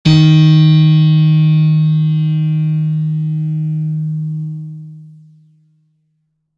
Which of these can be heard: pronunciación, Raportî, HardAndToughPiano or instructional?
HardAndToughPiano